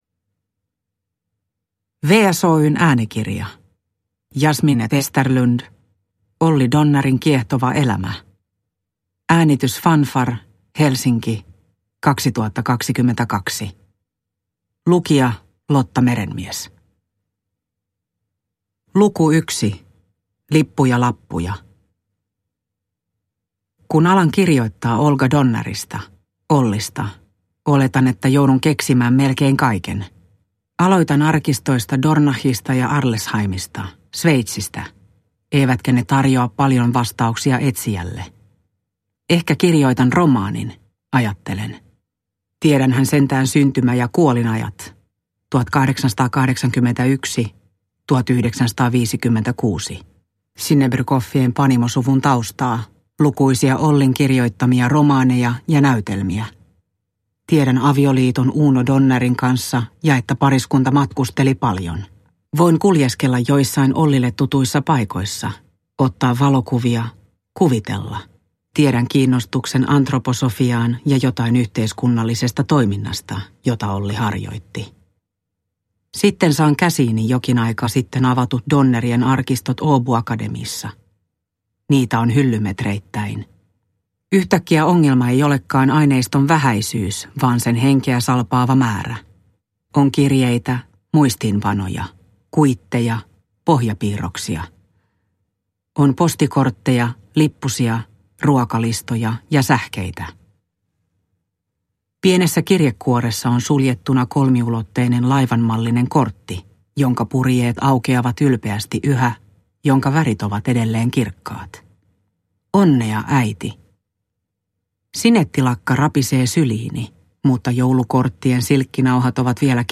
Olly Donnerin kiehtova elämä – Ljudbok – Laddas ner